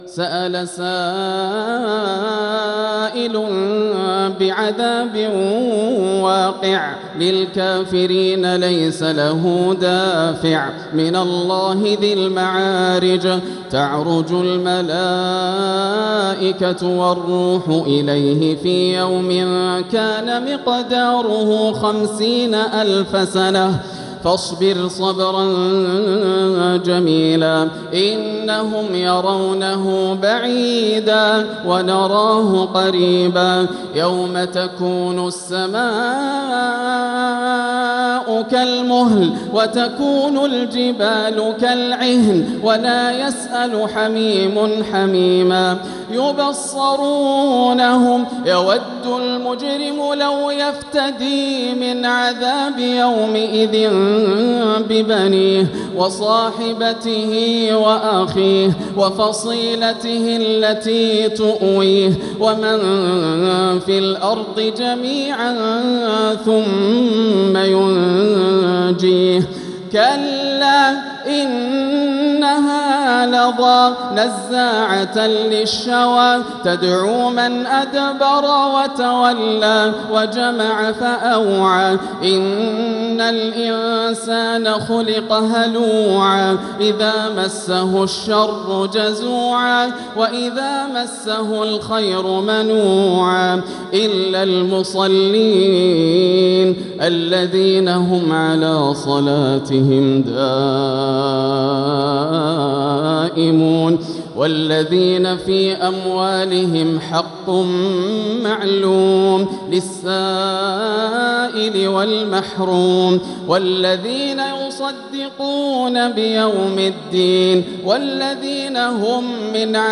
سورة المعارج | مصحف تراويح الحرم المكي عام 1446هـ > مصحف تراويح الحرم المكي عام 1446هـ > المصحف - تلاوات الحرمين